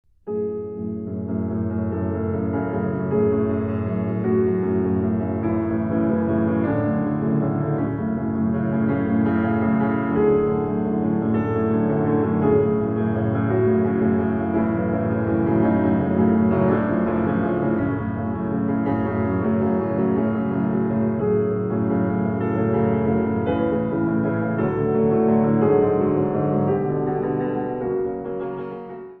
Neue Musik
Sololiteratur
Klavier (1)